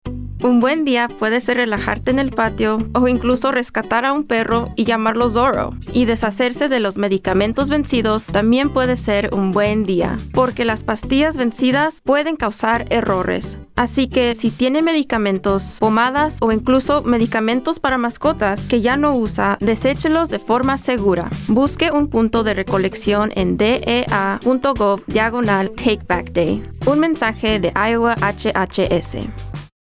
A Good Day | Radio Spot | FY26 | Spanish